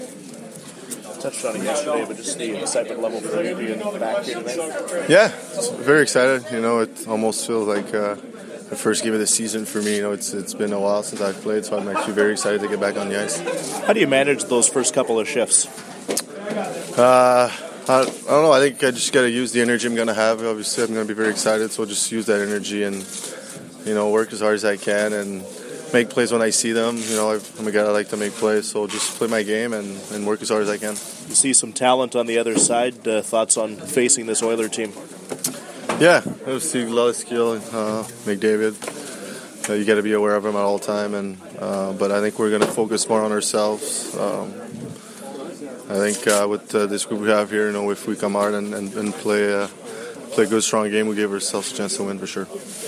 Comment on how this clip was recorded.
Player pre-game audio: All audio courtesy of TSN 1290 Winnipeg.